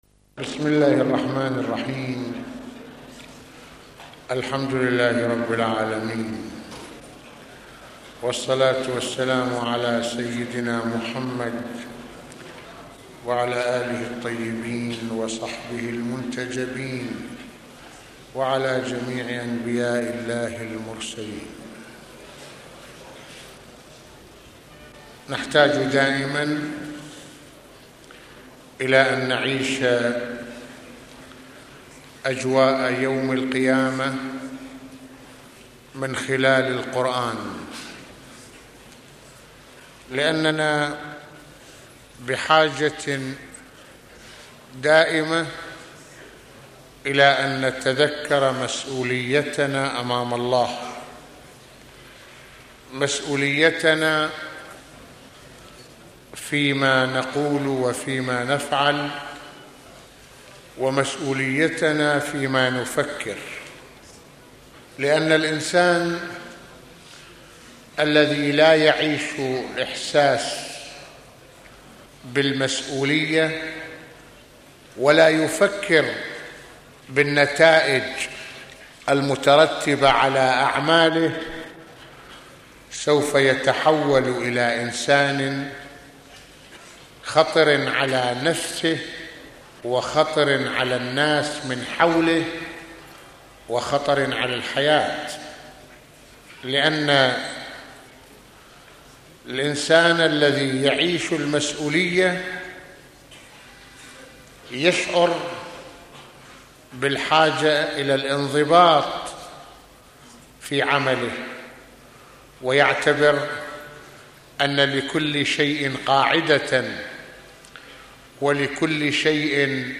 - يتحدّث العلامة المرجع السيّد محمد حسين فضل الله(رض) في هذه المحاضرة، عن مسؤولية الإنسان تجاه ربه، وضرورة تلازم الإيمان والعمل الصّالح، كما يتكلم عن مراقبة الإنسان للّه في كلّ خطواته، وانعكاس ذلك على عمله وتصرفاته. ثم يشرح سماحته عدّة آيات من سورة الجاثية (من آية 27 حتى آخر السورة)، التي تصف أهوال يوم القيامة، ونتائج أعمال الإنسان الّتي ستدخله الجنة أو النار.